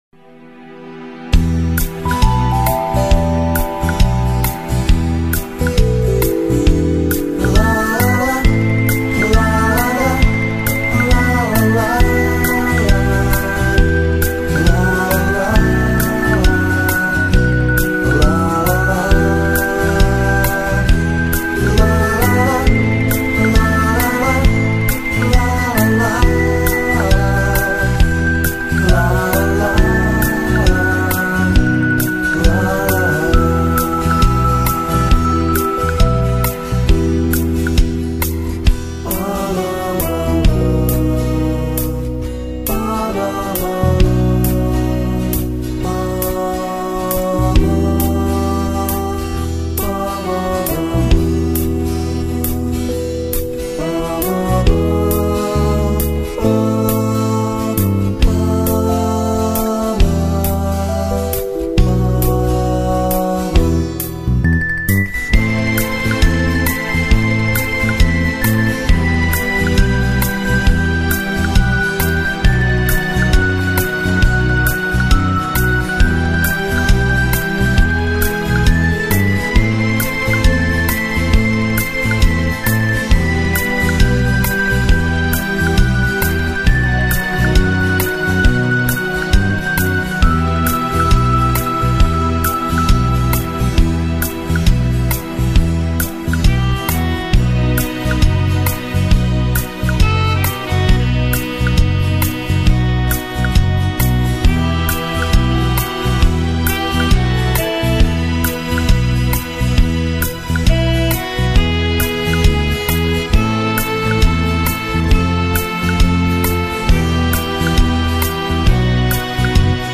Качество:Ориг+бэк